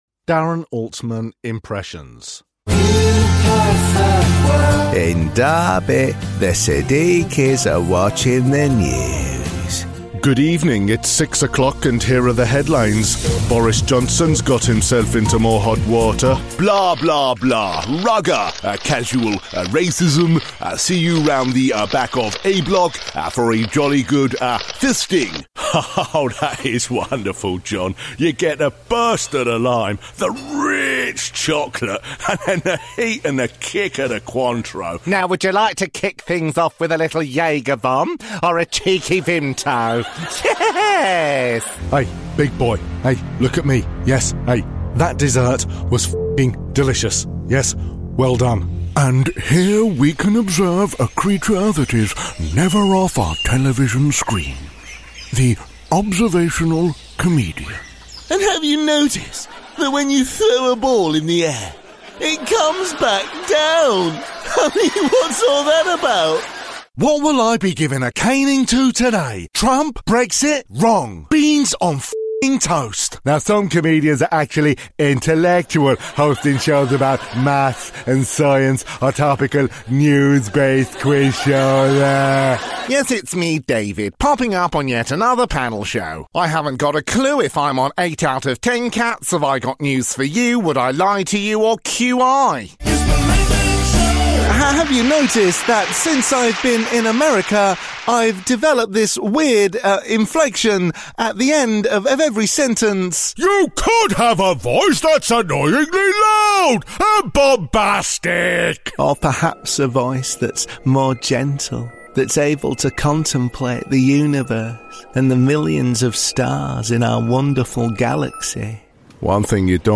English (British)
Impersonations
Mic: Neumann U87